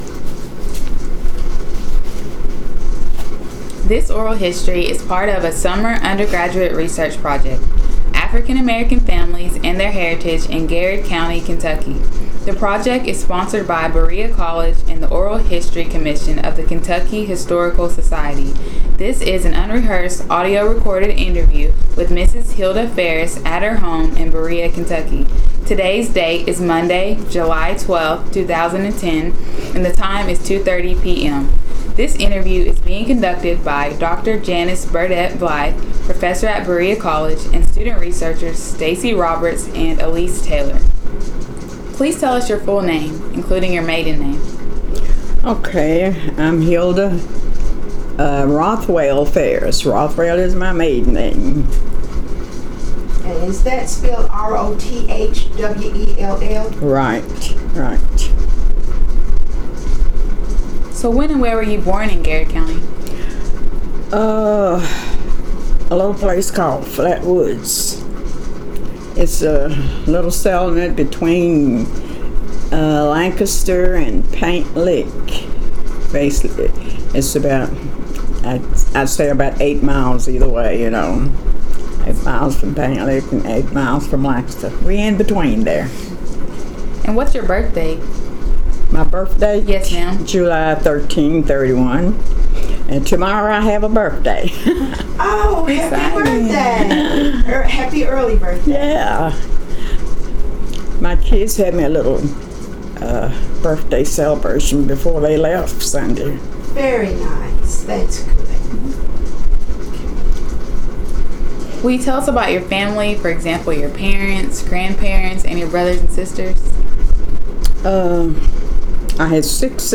Kentucky Historical Society